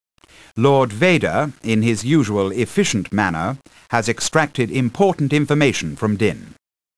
―An Imperial briefing officer, following Din's capture — (audio)
BriefingOfficer-DinInfo-TIEFighter.ogg